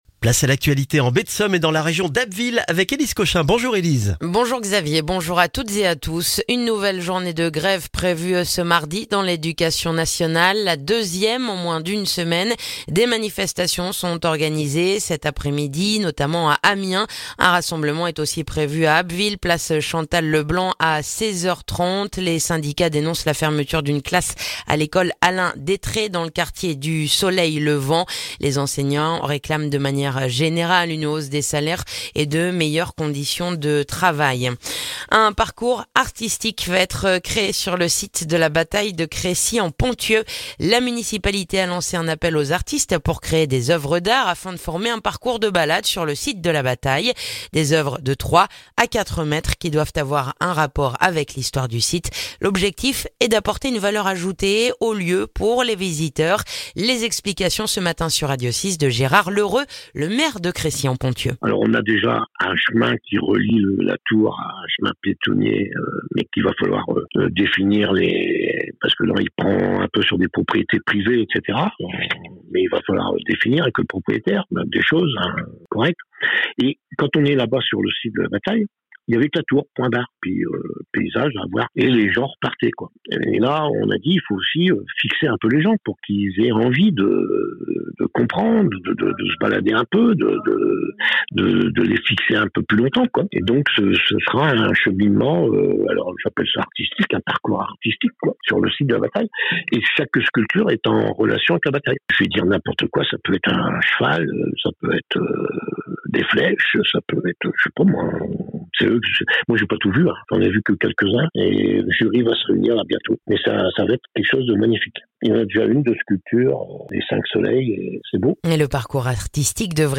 Le journal du mardi 6 février en Baie de Somme et dans la région d'Abbeville